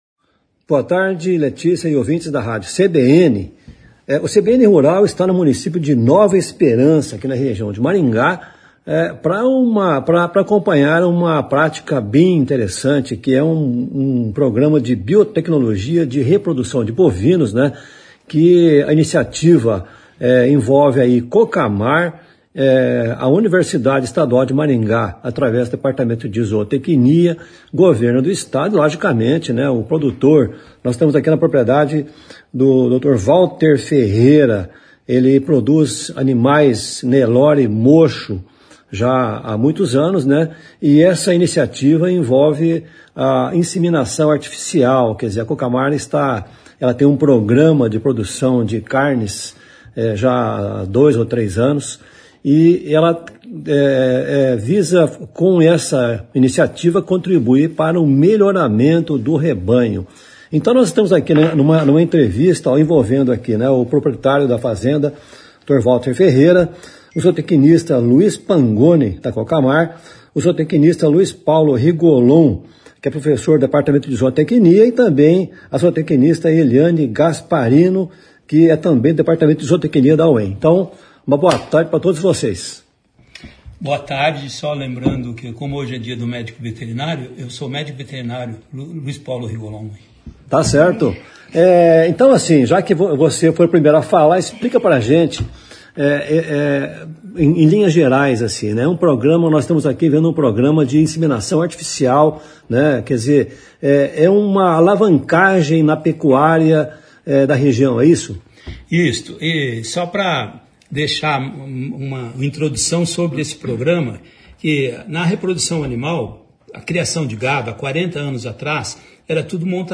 conversa com o zootecnista